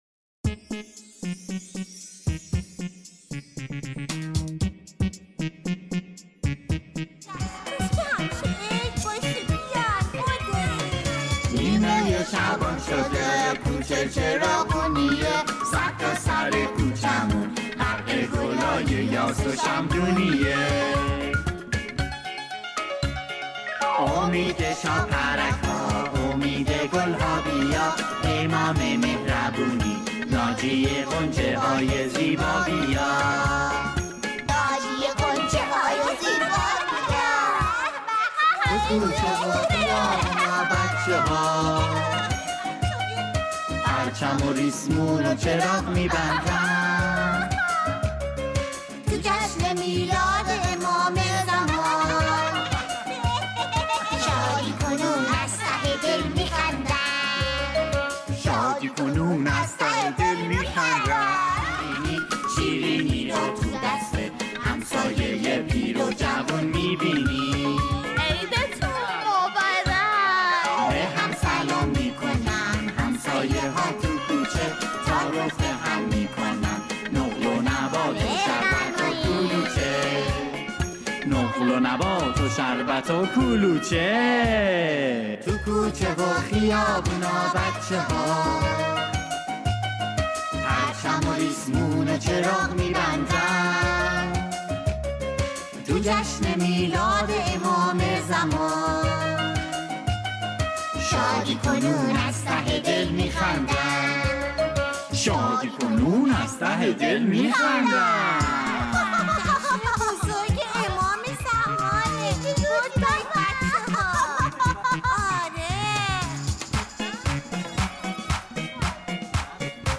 مولودی نیمه شعبان کودکانه
آهنگ کودکانه